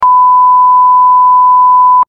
Beep
Beep.mp3